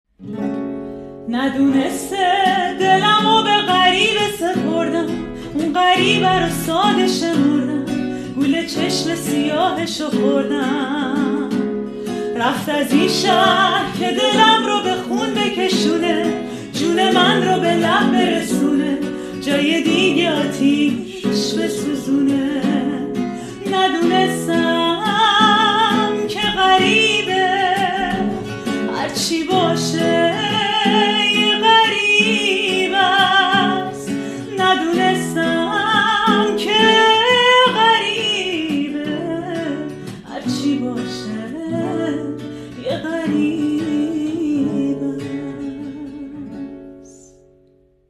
با صدای دختر